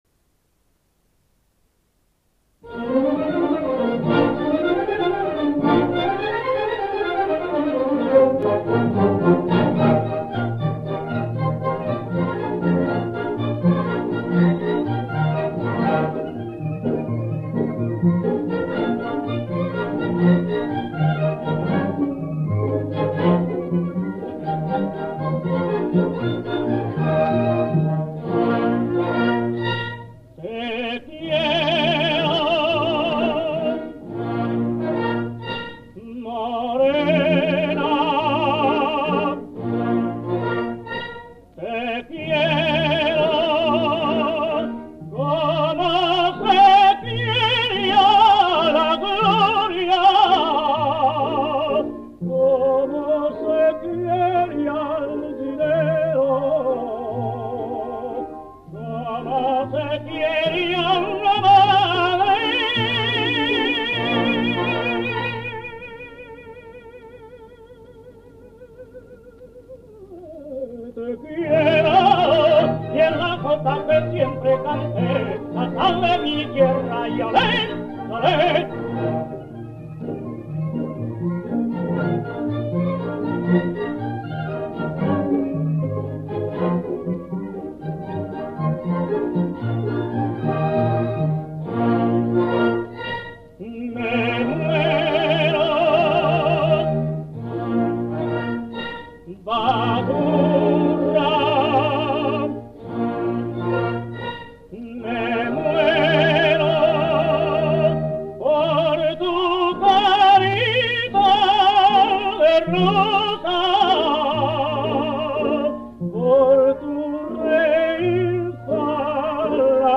Banda o grupo elegido: Grupo de Jota Aragonesa (Diversos joteros/as)
Esta entrada ha sido publicada en Jotas de Aragón y etiquetada como .